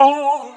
描述：这是一组短语，动机，人声，效果和其他名称。使用“SawCutter 1.0”的效果，循环，间距和ADSR
标签： 采样器 语音 词组
声道立体声